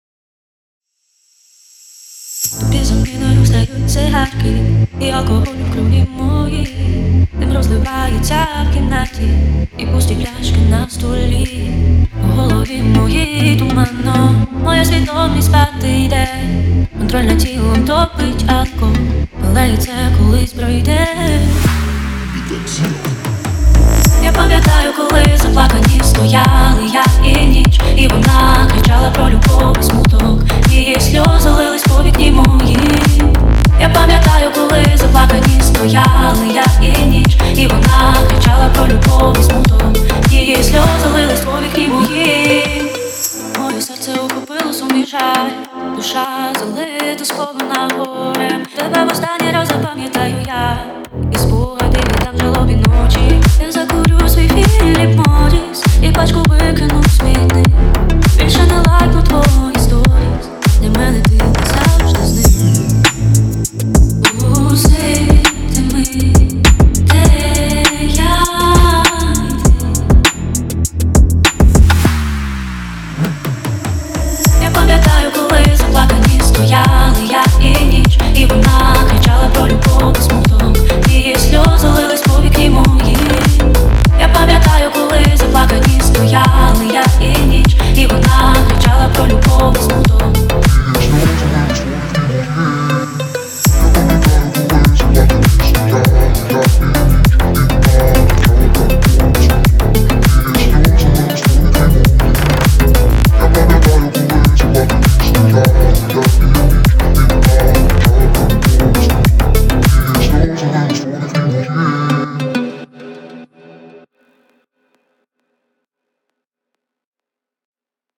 • Жанр: Українська музика